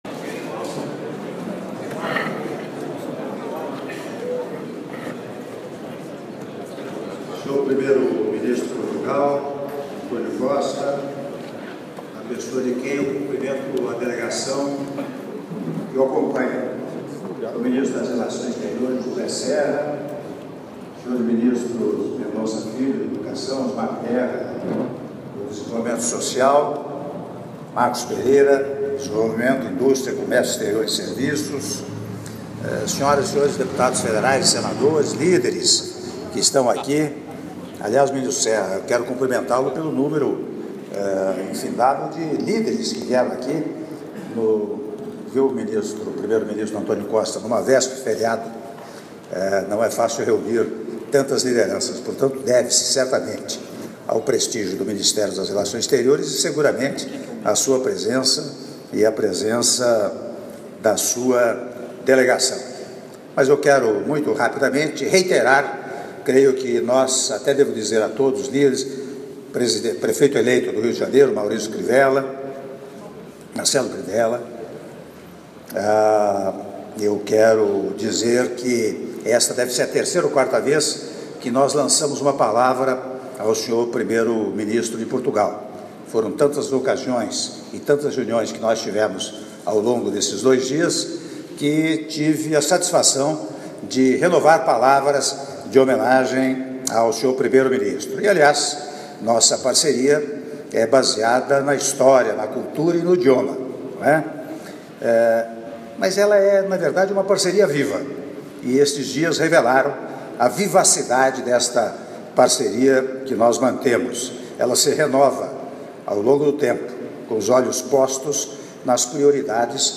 Áudio do brinde do senhor presidente da República, Michel Temer, durante jantar em homenagem ao senhor António Costa, primeiro-ministro de Portugal - Brasília/DF (04min06s)